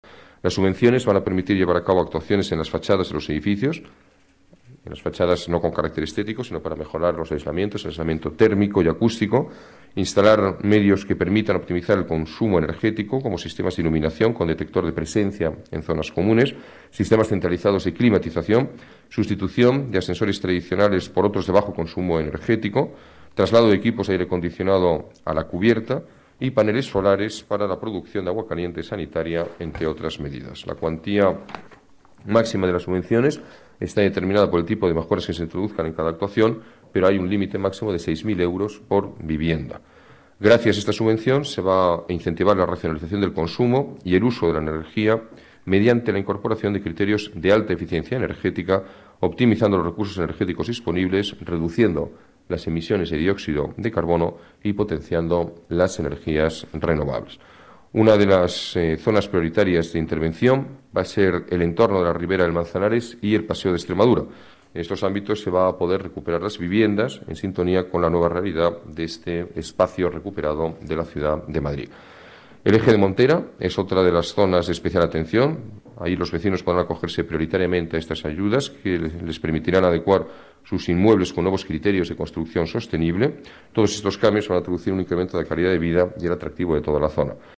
Nueva ventana:Declaraciones del alcalde, Alberto Ruiz-Gallardón: ayudas a la rehabilitación sostenible